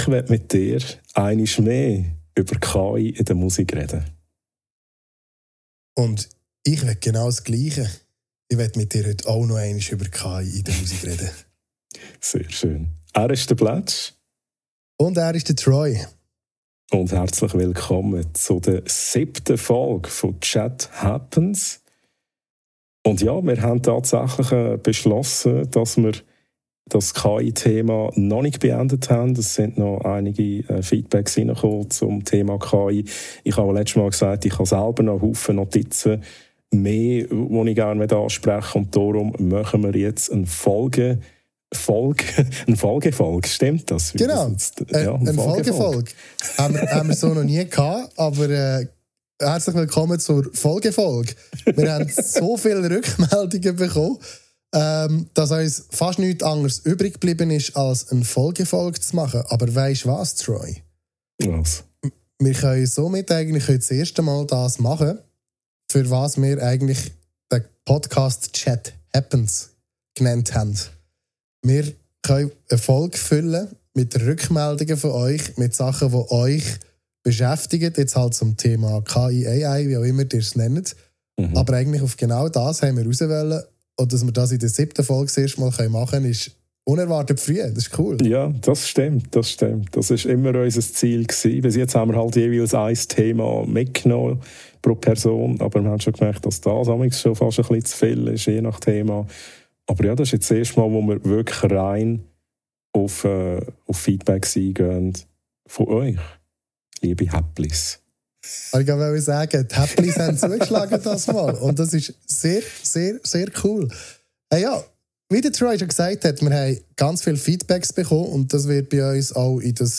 Schweizerdeutsch, reflektiert und gut vernetzt – viel Spass mit Episode 7!